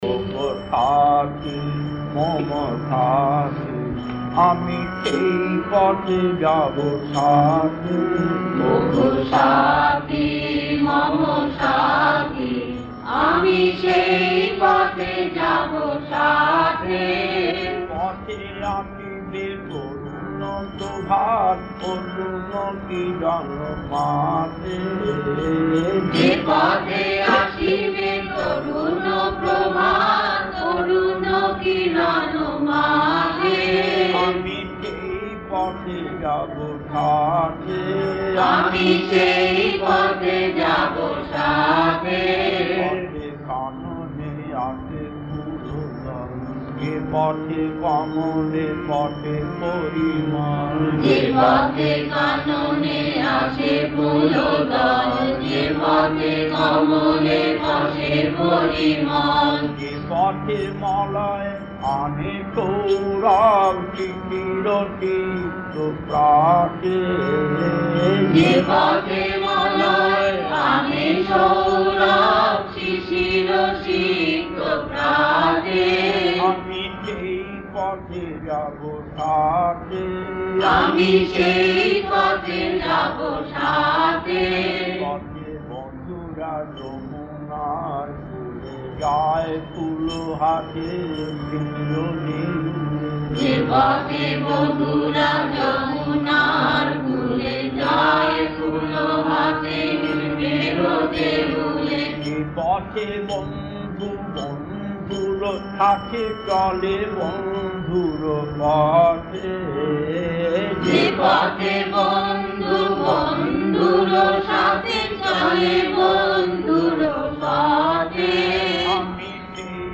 Kirtan D6-2 1.